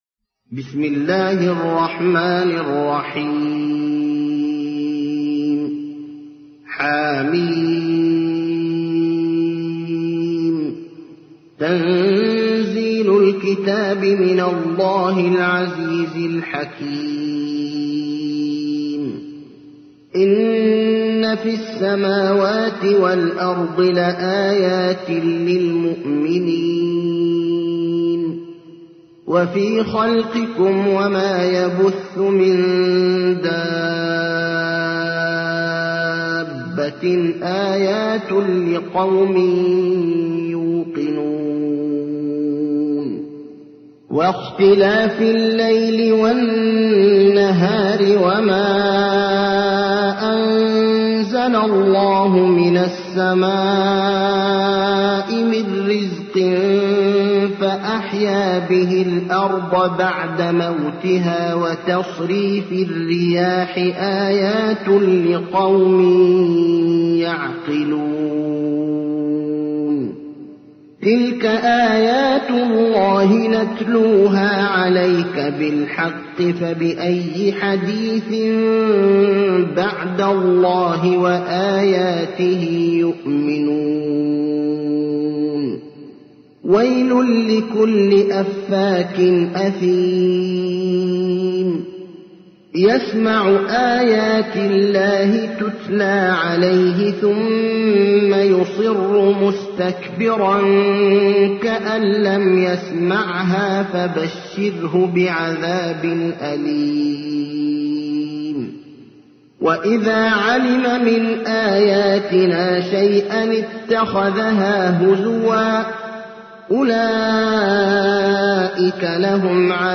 تحميل : 45. سورة الجاثية / القارئ ابراهيم الأخضر / القرآن الكريم / موقع يا حسين